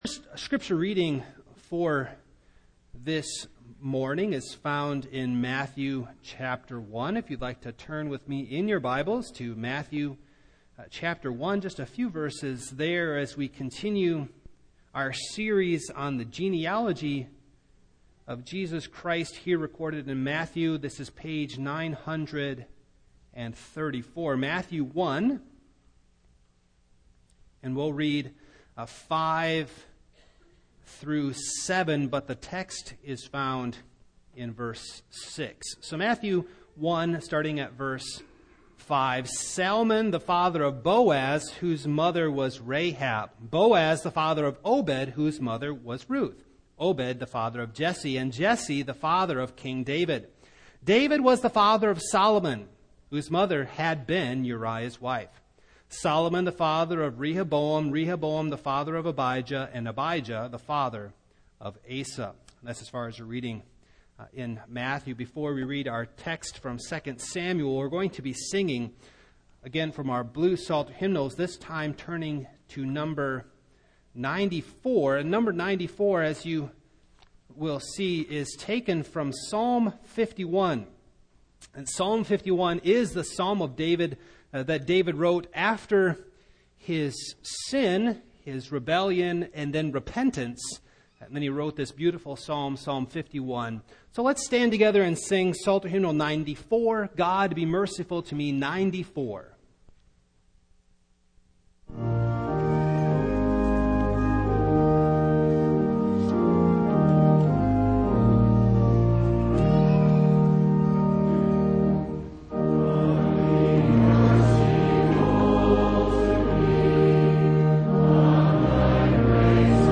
Single Sermons
Service Type: Morning